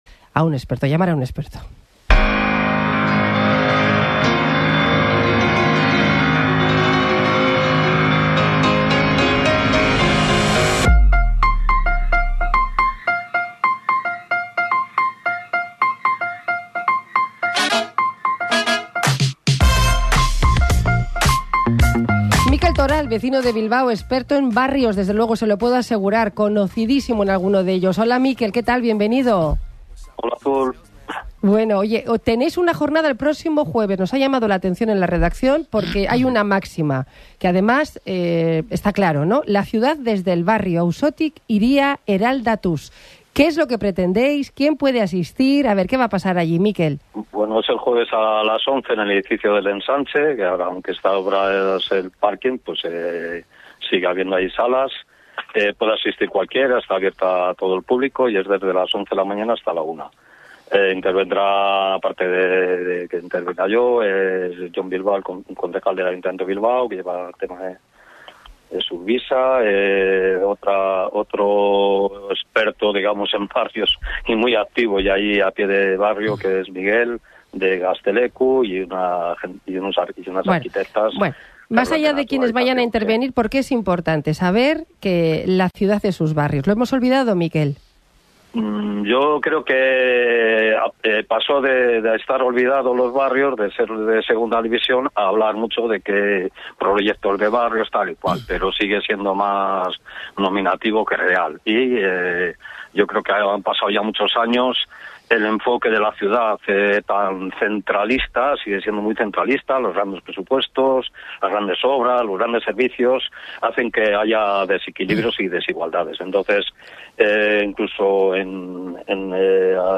by | Sep 9, 2025 | Interviews | 0 comments